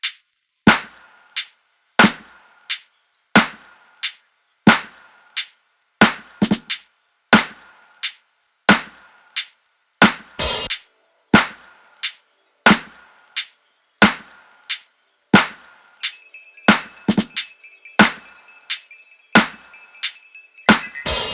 Park Topper Perc Loop.wav